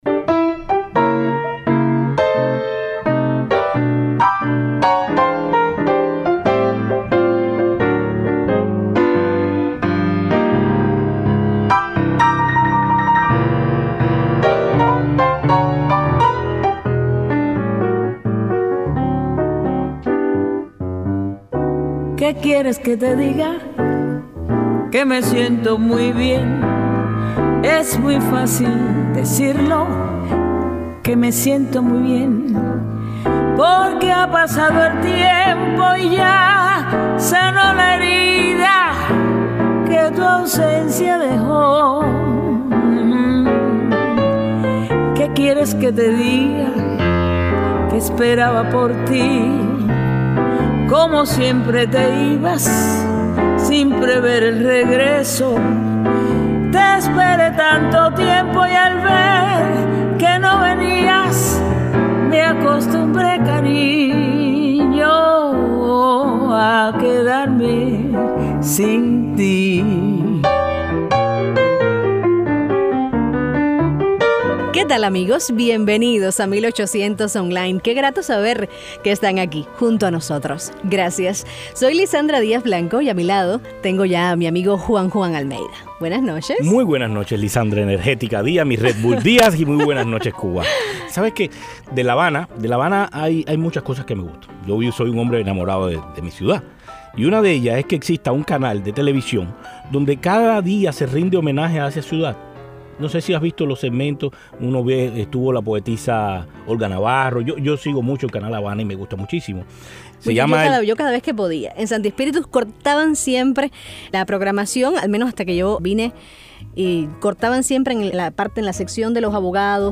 Periodista oficial cubano conversa con Radio Martí